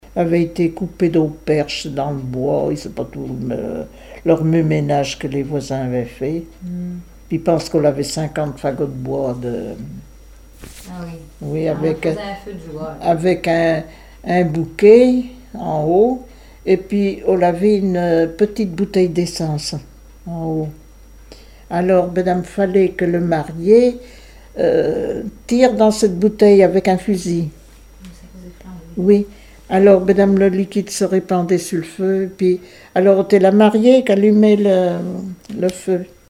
Langue Patois local
Catégorie Témoignage